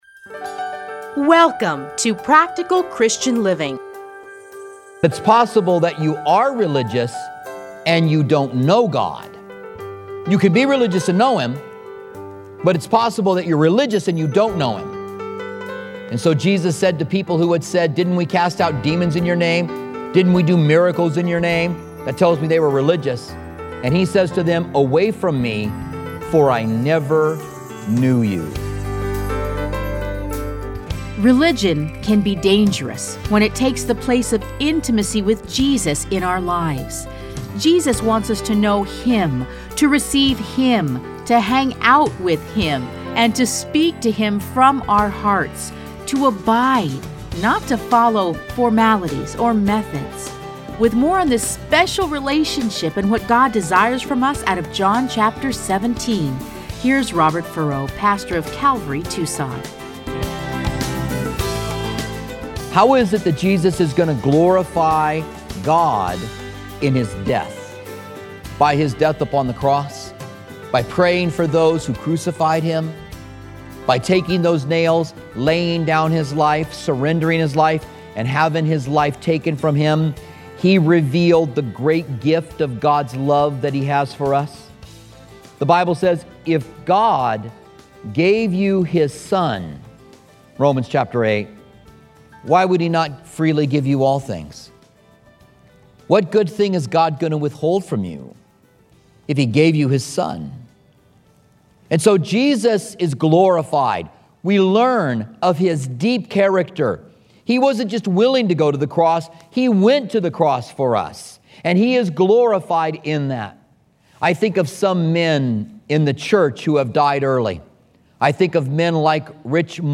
Listen to a teaching from John John 17:1-5.